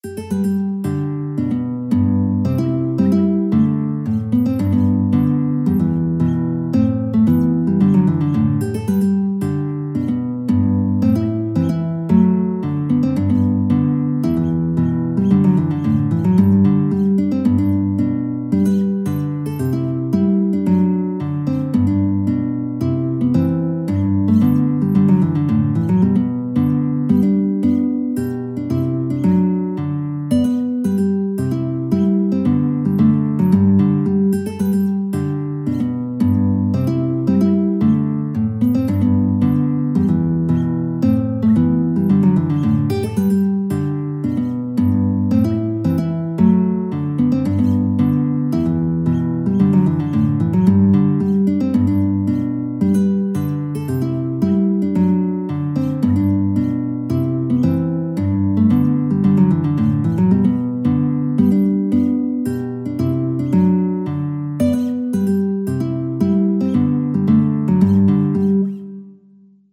G major (Sounding Pitch) (View more G major Music for Guitar )
4/4 (View more 4/4 Music)
Easy Level: Recommended for Beginners with some playing experience
Guitar  (View more Easy Guitar Music)
Traditional (View more Traditional Guitar Music)